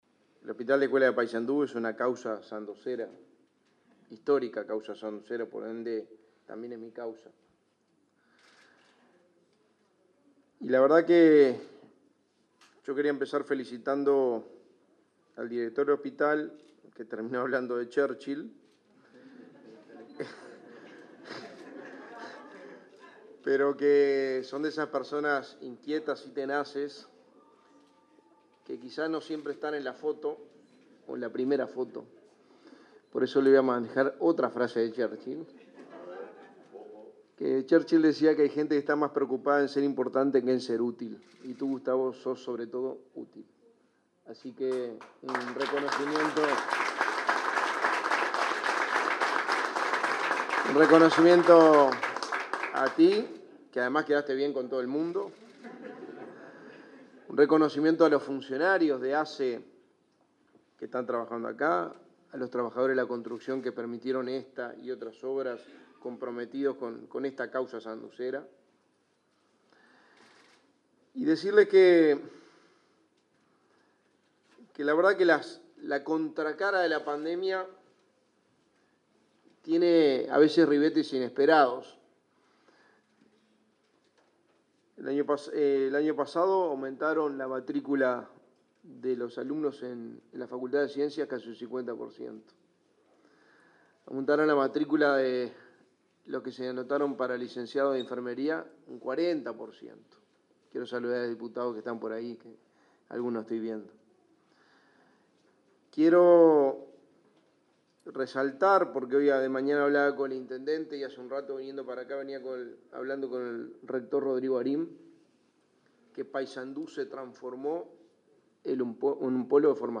Palabras del secretario de Presidencia, Álvaro Delgado
El secretario de Presidencia, Álvaro Delgado, participó este viernes 18 del acto de inauguración de un CTI del hospital de Paysandú.